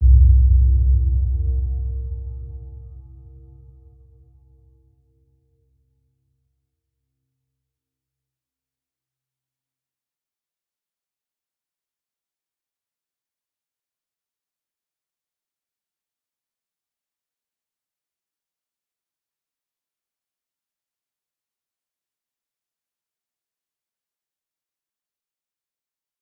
Round-Bell-C2-f.wav